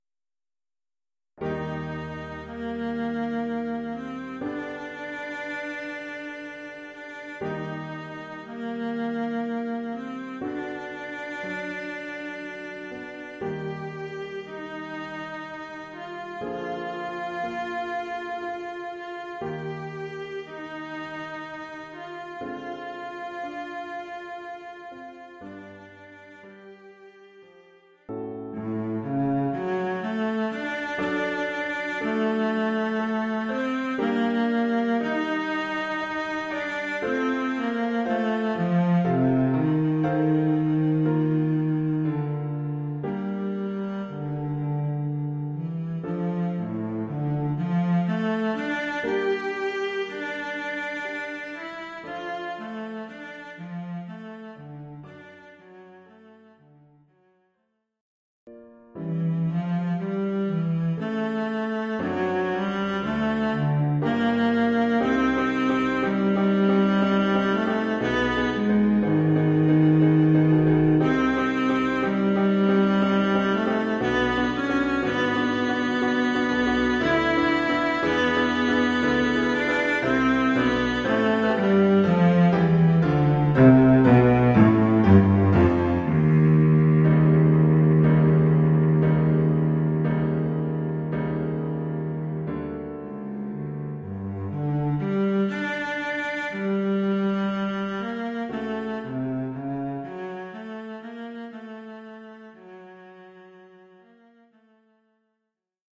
Oeuvre pour violoncelle et piano.